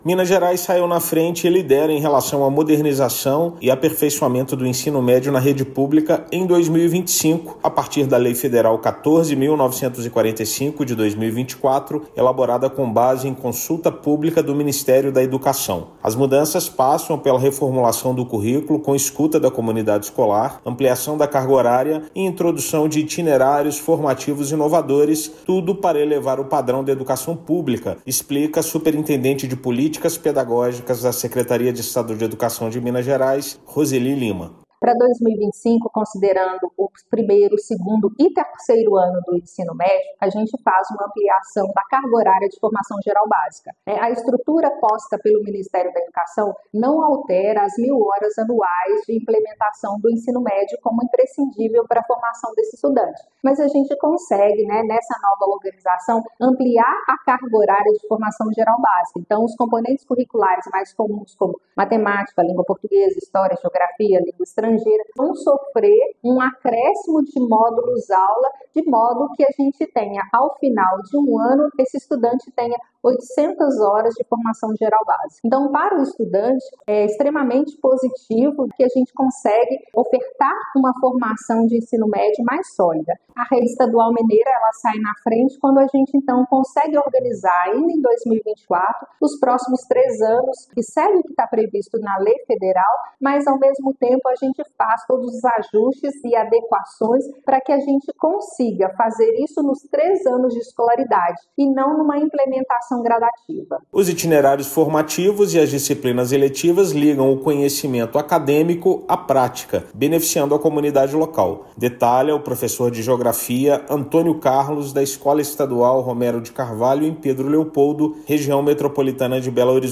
Estado se destaca ao reformular o currículo com base na escuta da comunidade escolar, ampliando a carga horária e introduzindo itinerários formativos inovadores, elevando o padrão da educação pública. Ouça matéria de rádio.